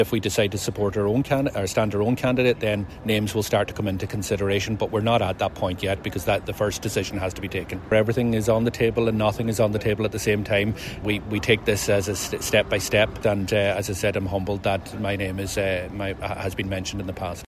Northern Ireland’s First Minister Michelle O’Neill’s name has been mentioned, while Deputy Doherty says he’s honored to be referred to as a possible perspective: